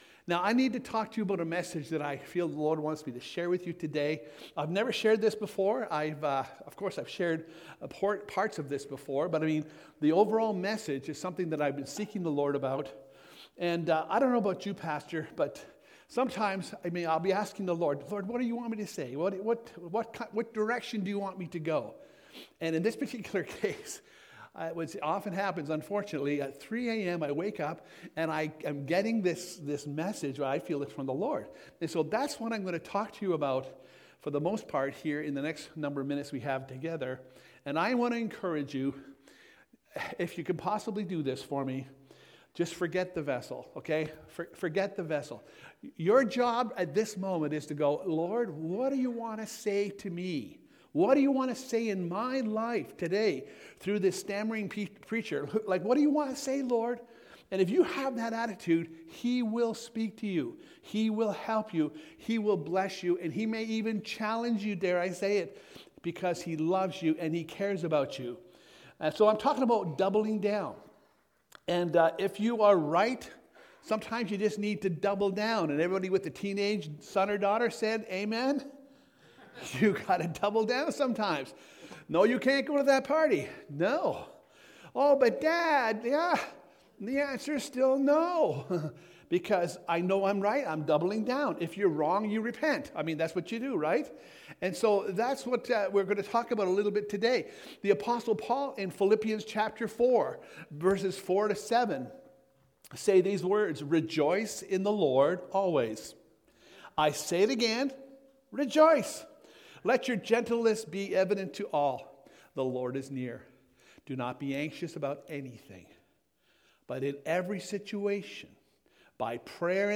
Sermons | Abundant Life Worship Centre